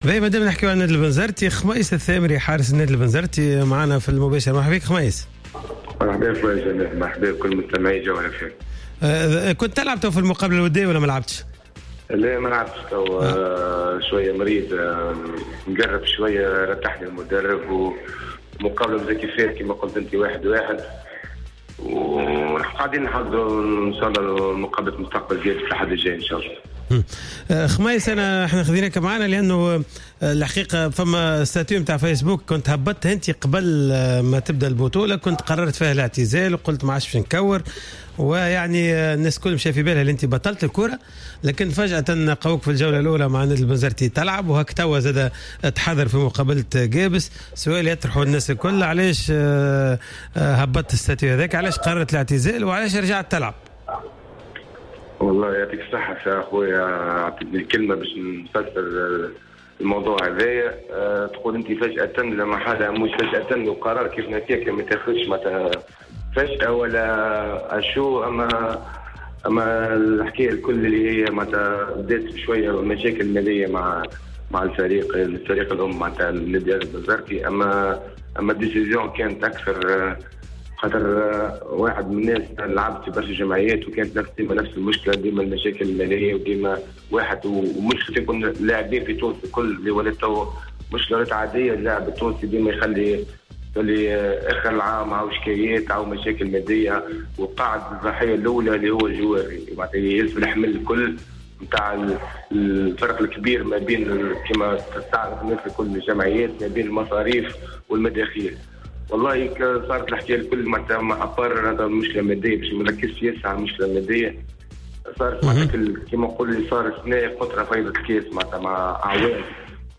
مداخلة في حصة "راديو سبور"